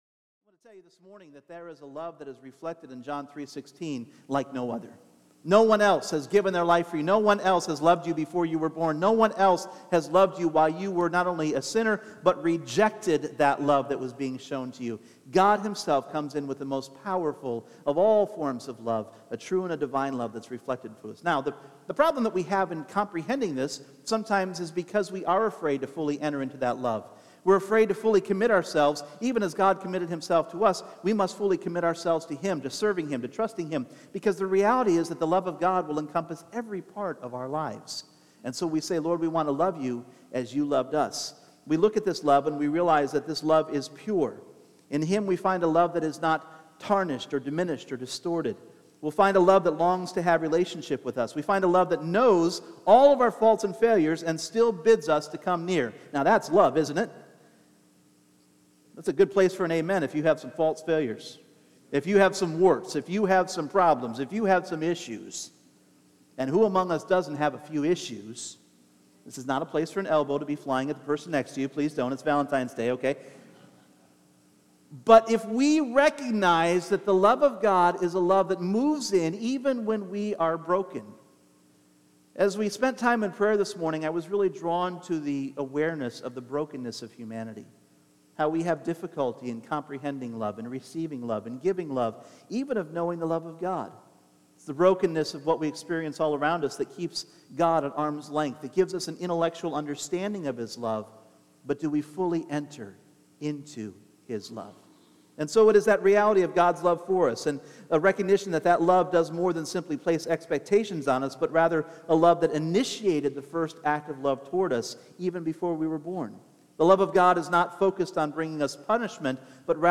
Individual Messages Service Type: Sunday Morning What strange ideas of love this world has!